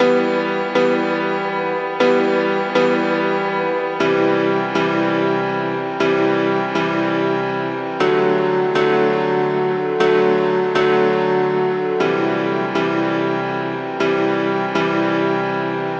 钢琴和弦
描述：E, C, D, C
Tag: 120 bpm Chill Out Loops Piano Loops 1.35 MB wav Key : E